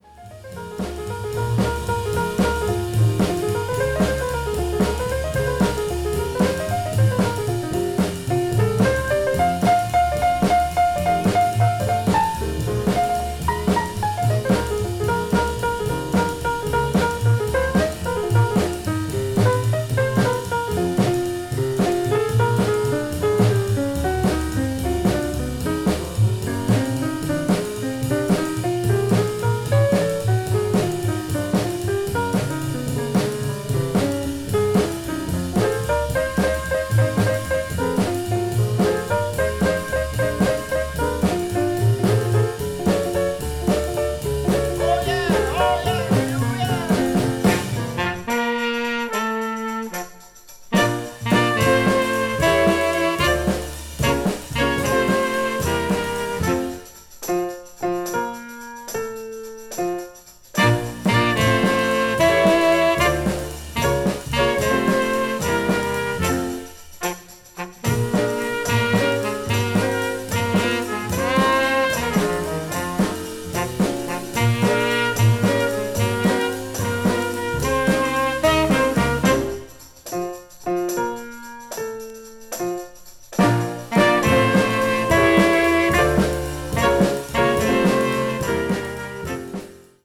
quintet
soul jazz